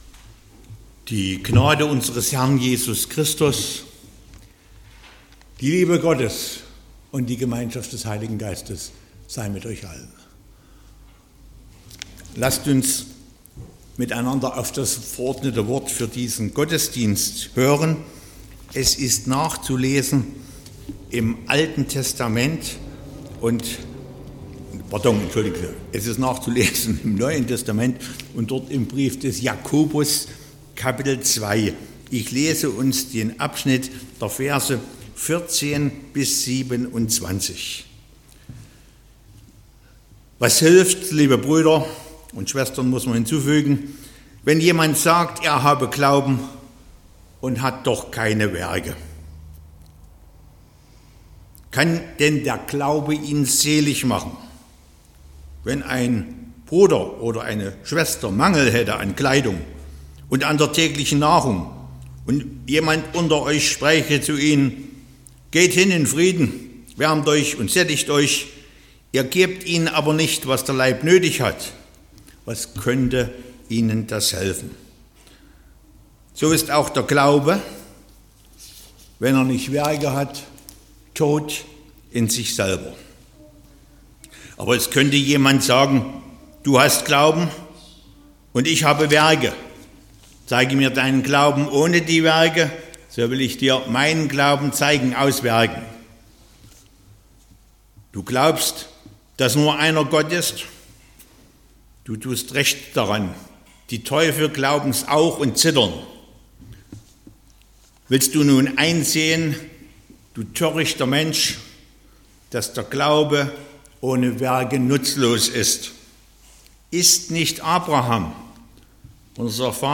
19.10.2025 – Gottesdienst
Predigt (Audio): 2025-10-19_Ja__aber____.mp3 (21,3 MB)